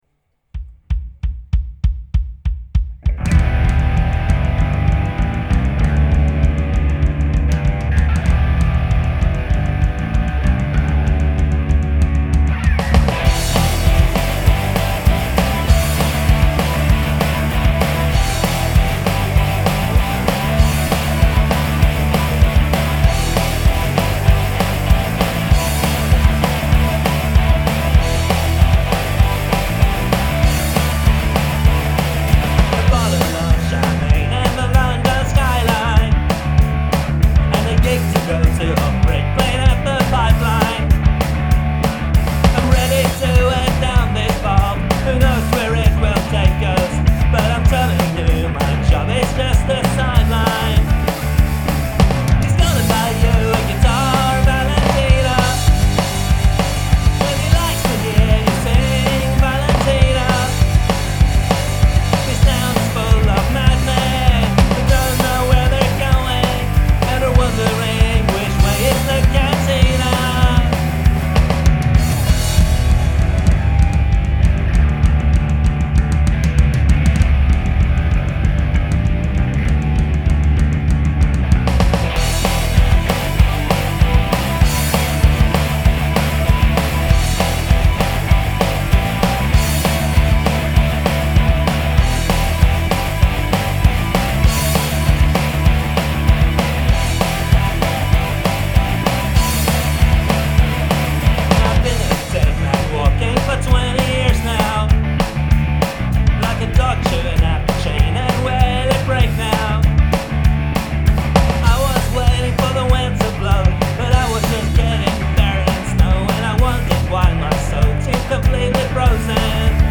rough and ready, quick and dirty
Hi guys!, I made this recording (fairly) quickly using presets I've painstakingly crafted over the years, and very little tweaking.
This is a one man band thingie
the drums are, I hope! - suitably, not too, rough